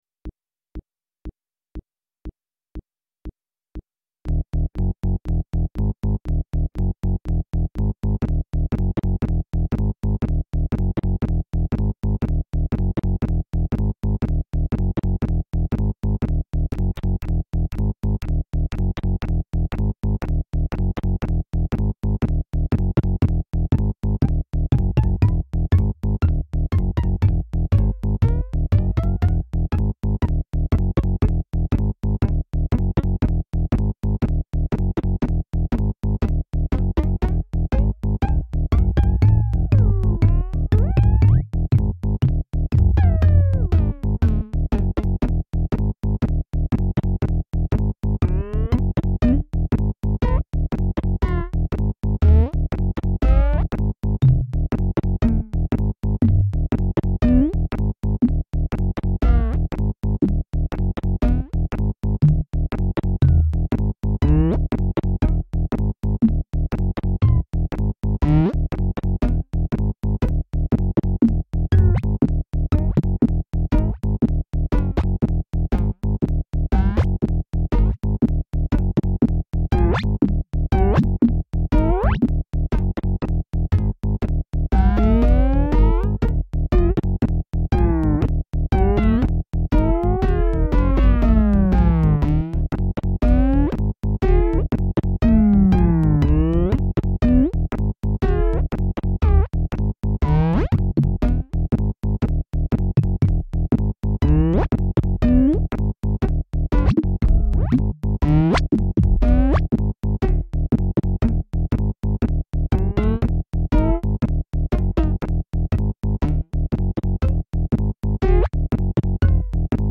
Hier gibt es meine Experimente mit Tönen und Geräuschen sowie diverser Hard- und Software zur Klangerzeugung.